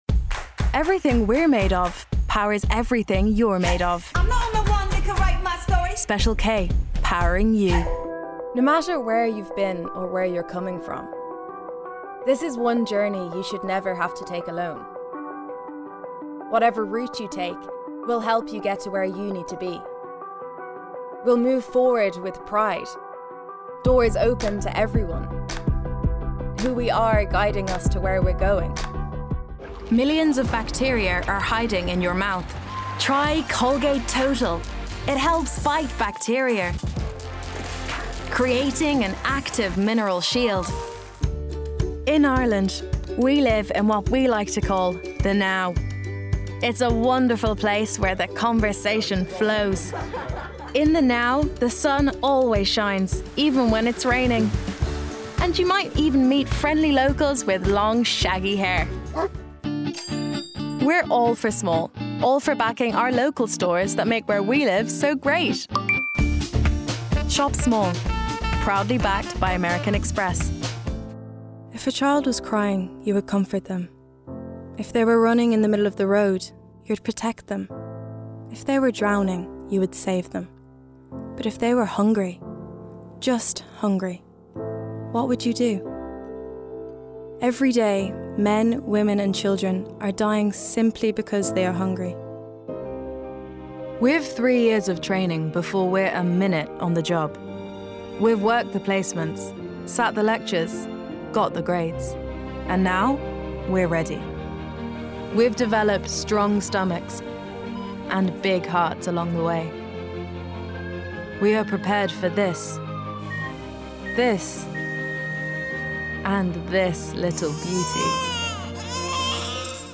20/30's Irish, Universal/Compelling/Cool